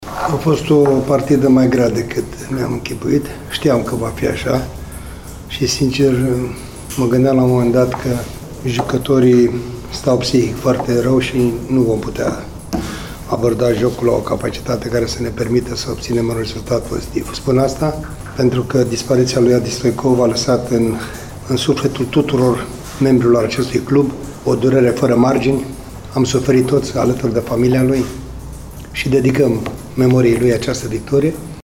În schimb, Ionuț Popa a alternat momentele serioase cu glumele despre viteza lui Șeroni și posibilitatea de a fi convocat la națională: